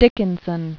(dĭkĭn-sən), Emily Elizabeth 1830-1886.